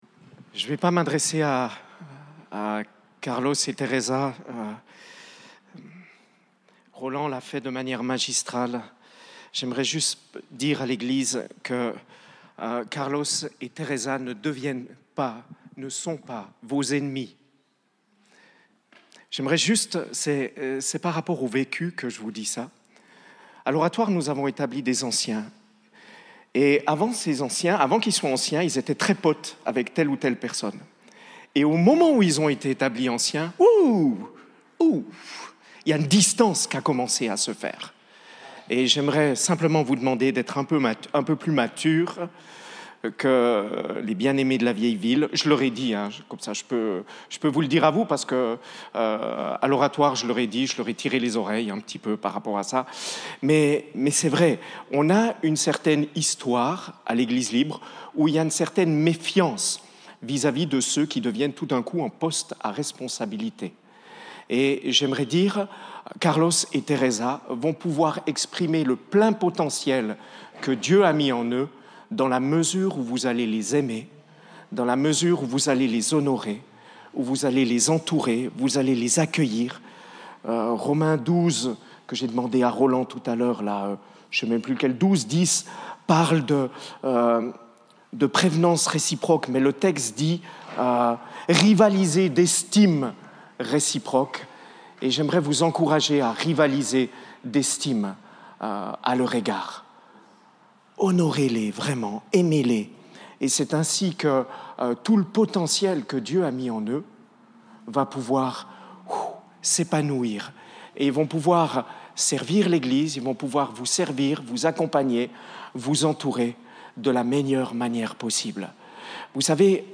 Interpellation et Temps de prière